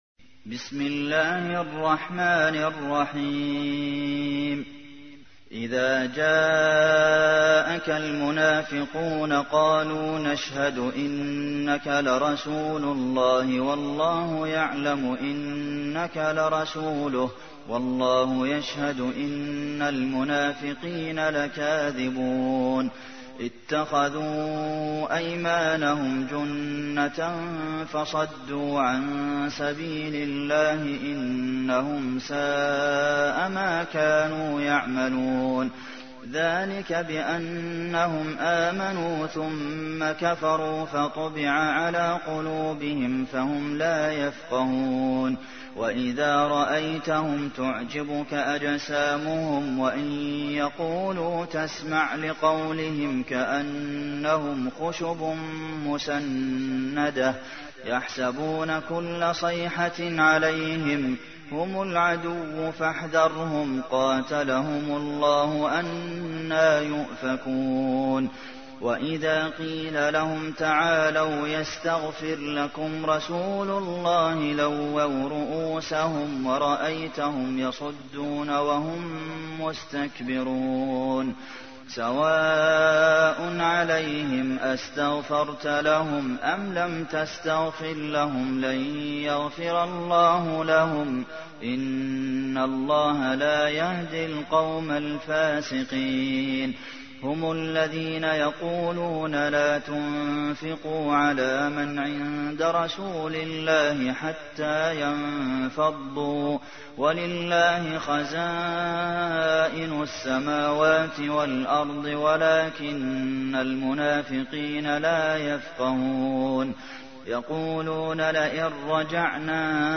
تحميل : 63. سورة المنافقون / القارئ عبد المحسن قاسم / القرآن الكريم / موقع يا حسين